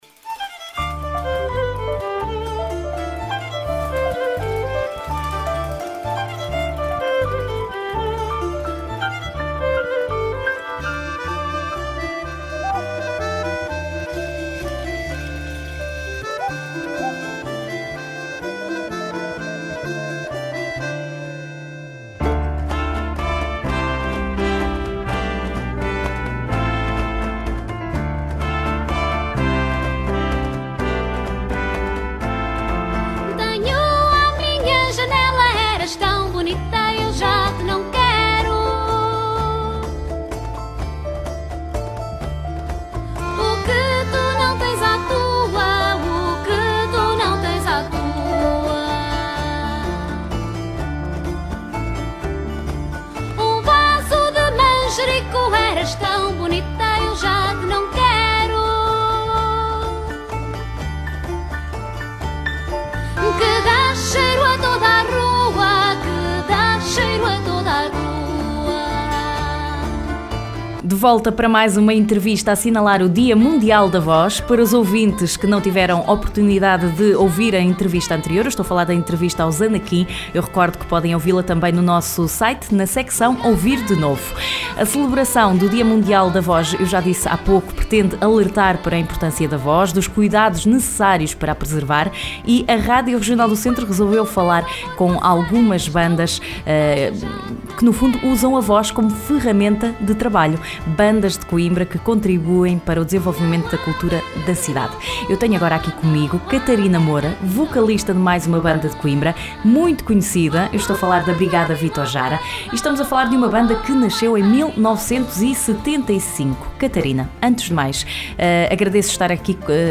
Entrevista à Brigada Victor Jara